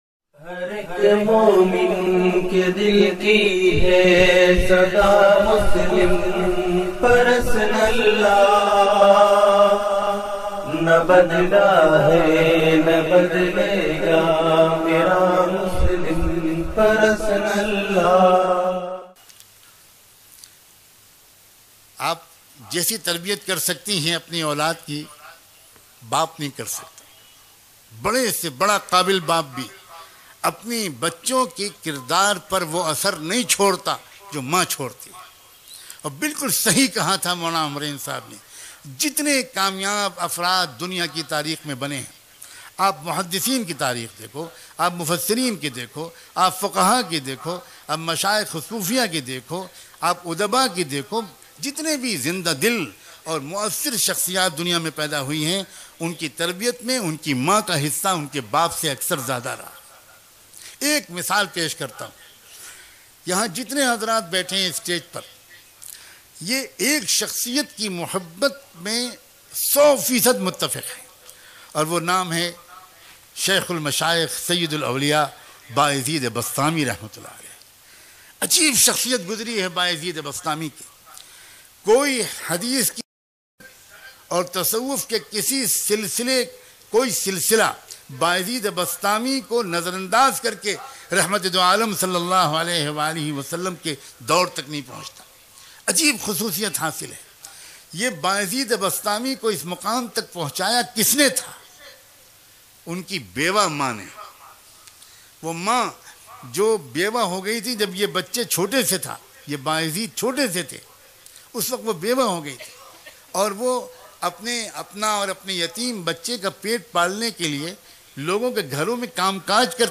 Very Emotional Story of BaYazeed Bastami Rahbayan mp3